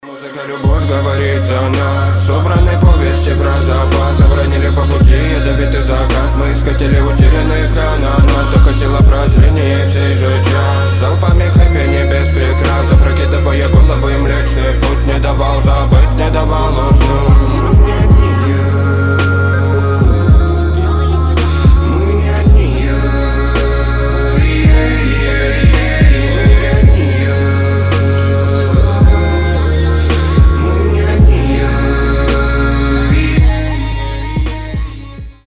• Качество: 320, Stereo
мужской вокал
Хип-хоп
мелодичные
романтичные